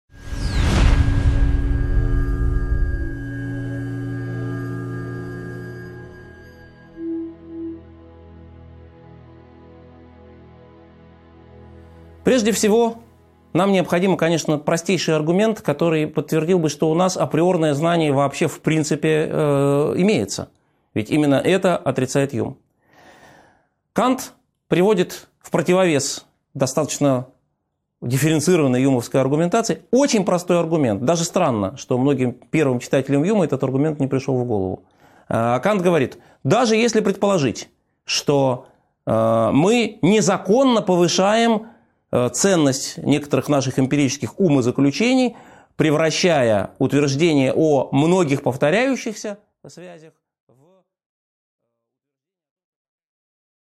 Аудиокнига 10.6 Программа трансцендентализма (окончание) | Библиотека аудиокниг